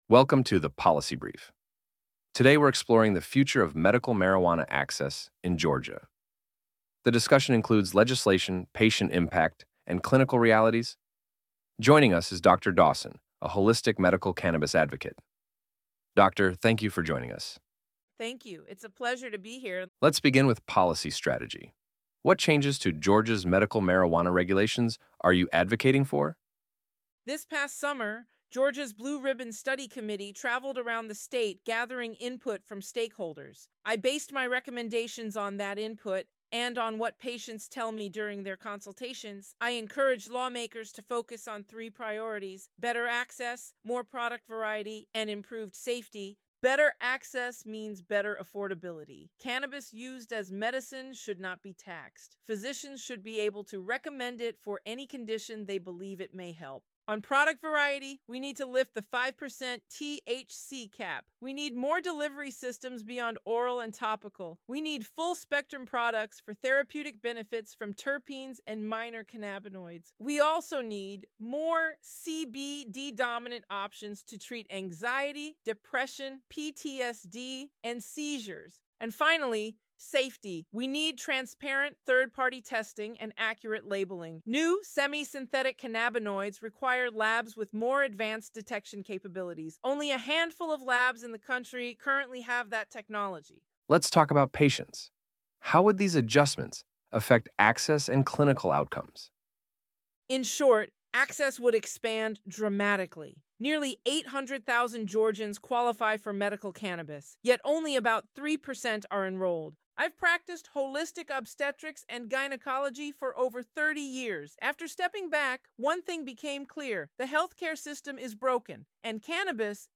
Clinical Perspectives on Georgia’s Medical Marijuana Laws (Podcast Q&A)